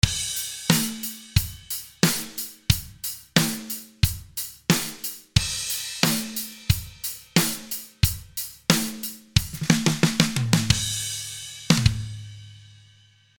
19:36:56 » Тут дело в том, что чтобы получить такой же эффект как у Тейлора нужно в момент открытия еще и палочкой ударить, а везде обычно просто педалью открывают отпуская её и не бьют палочкой по хету.